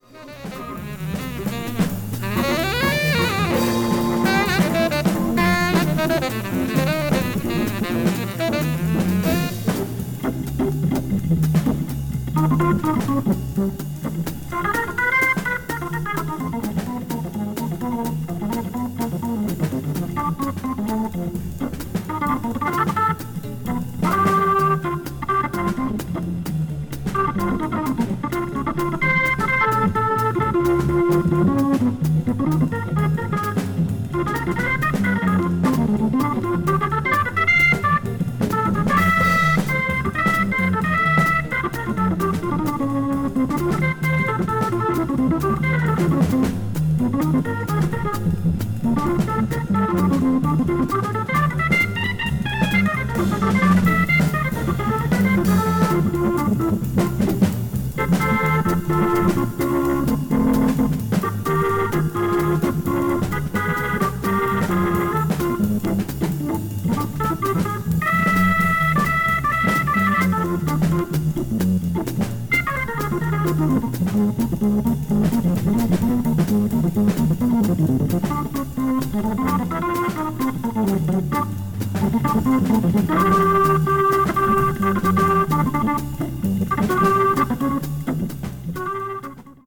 media : EX/EX(わずかにチリノイズが入る箇所あり)
スタンダードをしっとりとエレガントに奏でたA2も良いです。